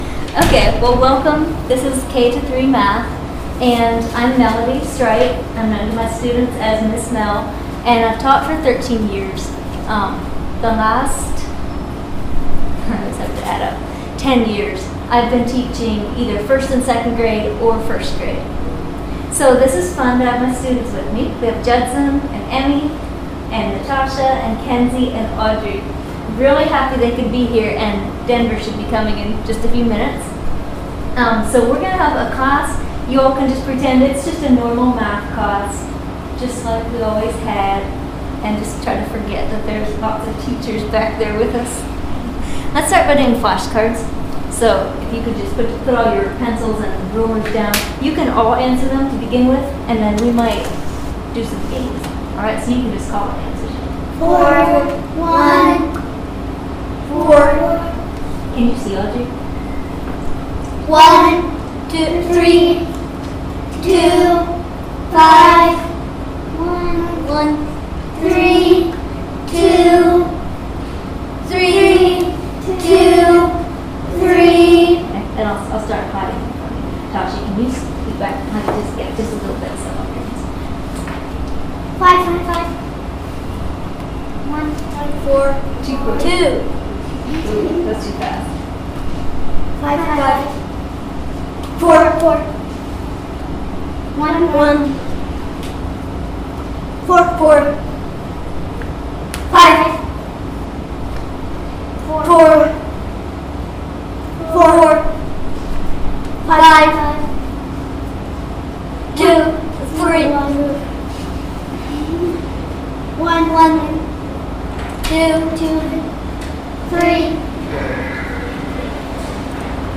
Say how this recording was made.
2025 Midwest Teachers Week 2025 Recordings K-3 Math Audio 00:00